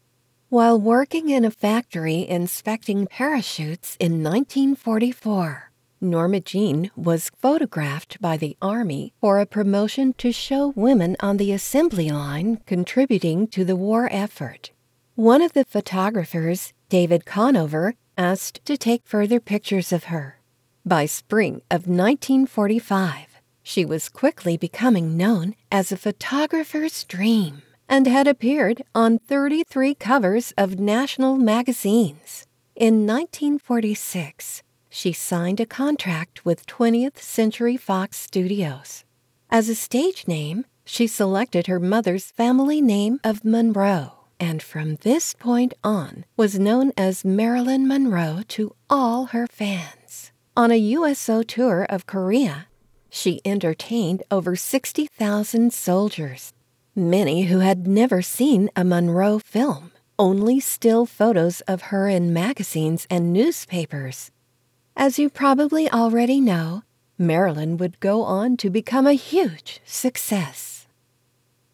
Articulate, caring, joyful, dramatic
Narration
I have my own home studio and also do my own editing, resulting in quick turnaround time.